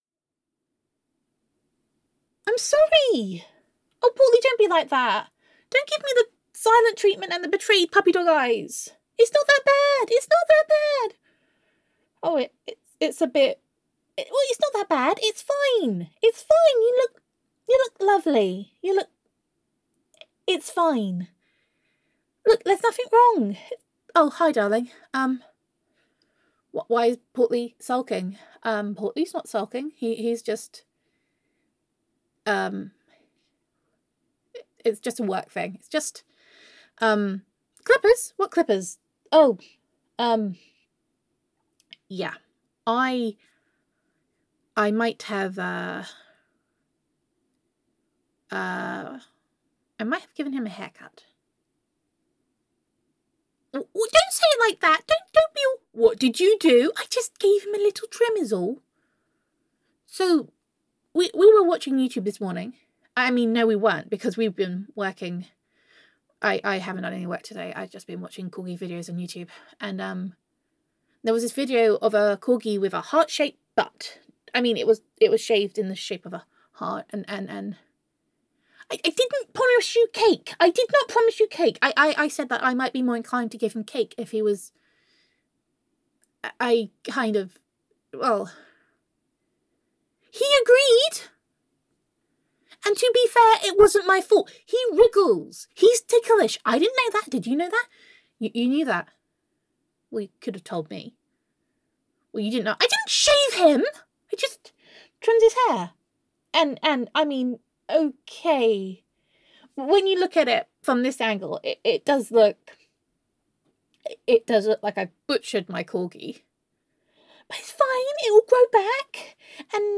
[F4A] Day Five - Shaved Corgi Butt [Girlfriend Roleplay][Self Quarantine][Domestic Bliss][Gender Neutral][Self-Quarantine With Honey]